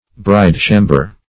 Bridechamber \Bride"cham`ber\, n. The nuptial apartment.